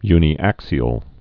(ynē-ăksē-əl)